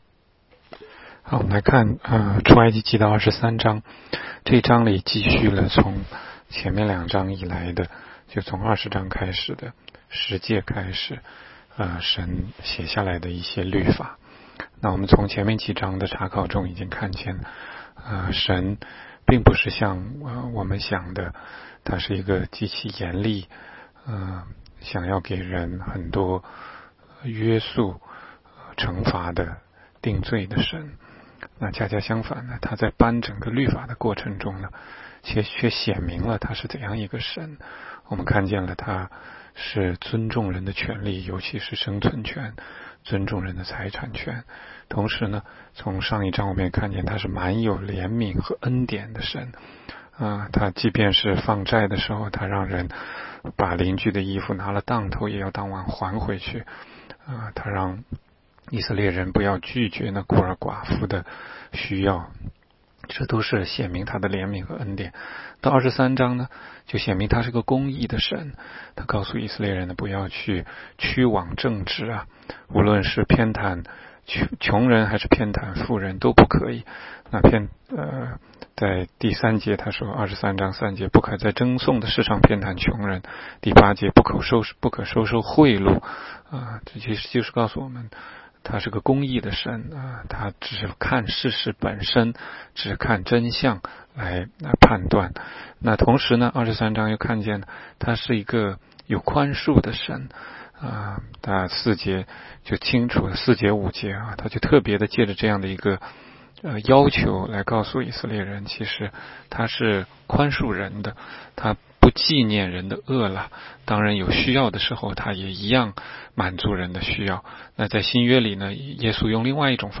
16街讲道录音 - 每日读经
每日读经-出23章.mp3